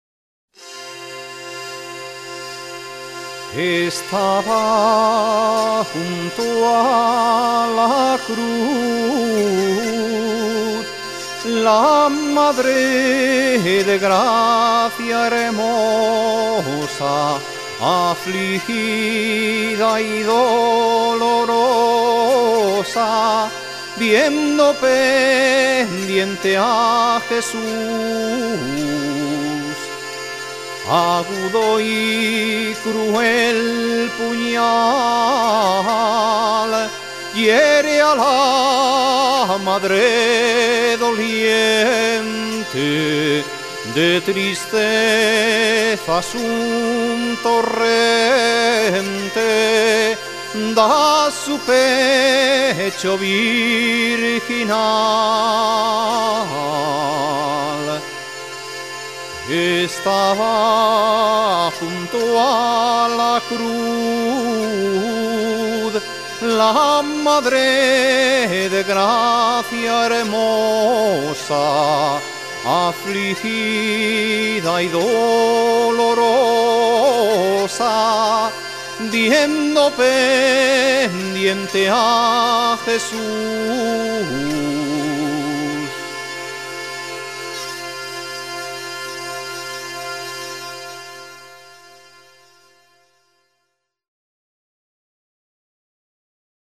Zanfona y voz